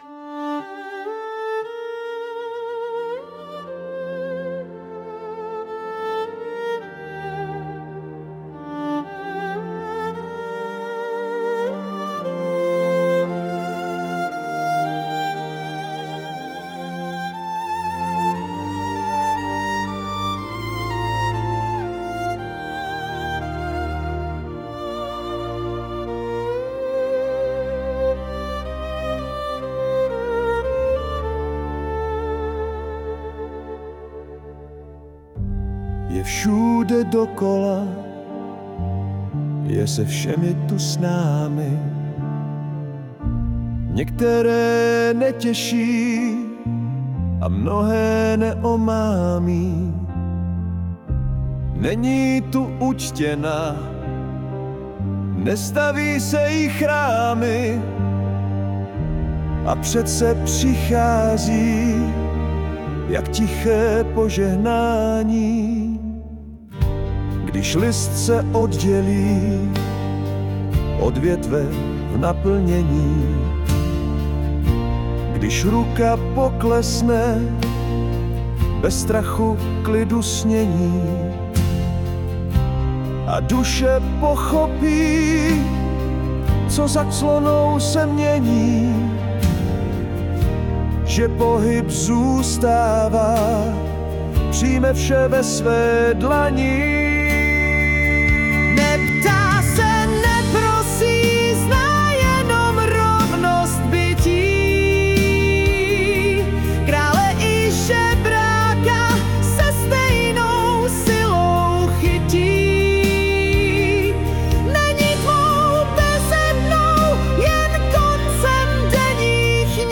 je to takové tiché konstatování s nádechem smutku a přesto v duchu jisté pokory
hudbu pomocí AI